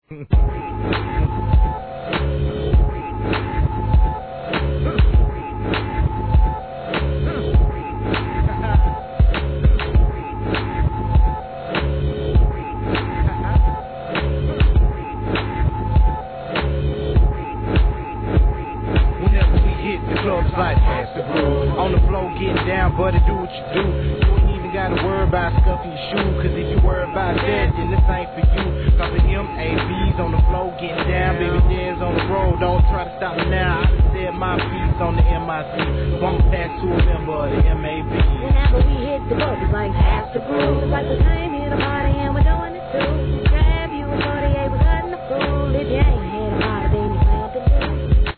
HIP HOP/R&B
SOUTHとは言え、一般的に想像するCRUNKサウンドとは違い、まったく違う角度からのアプローチ！！